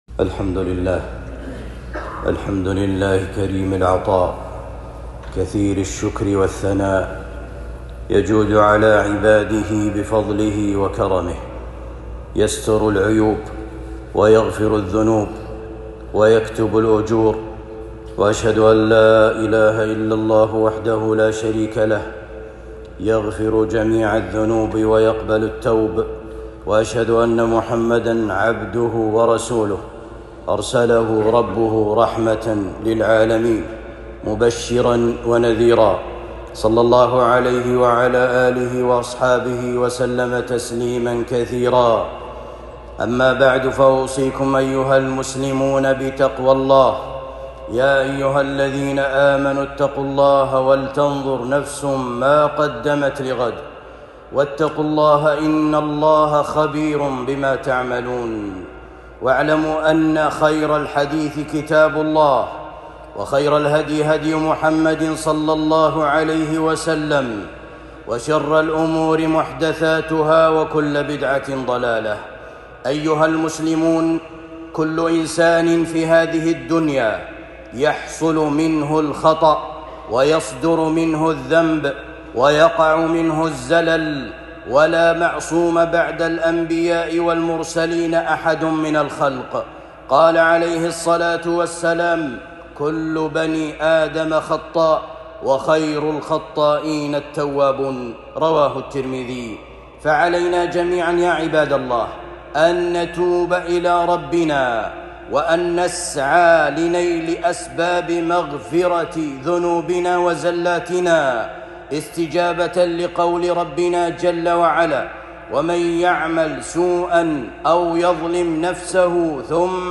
خطبة جمعة بعنوان أسباب مغفرة الذنوب